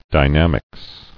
[dy·nam·ics]